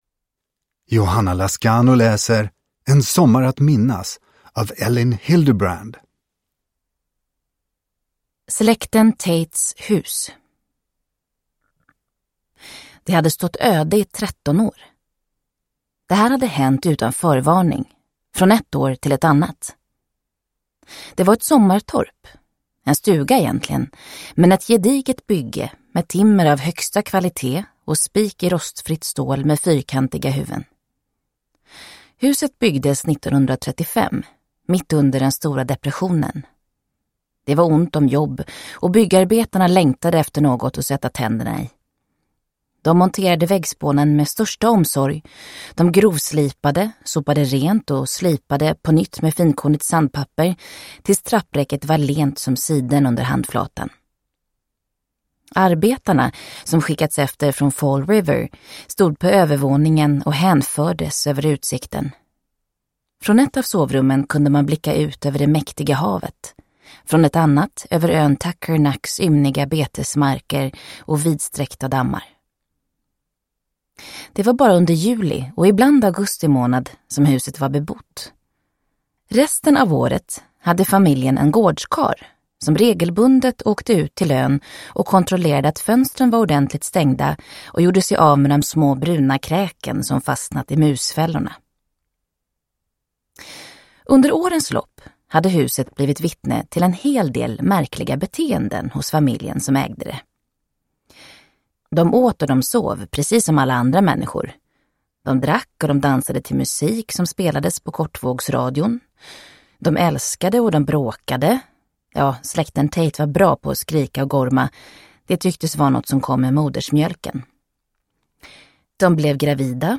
En sommar att minnas – Ljudbok – Laddas ner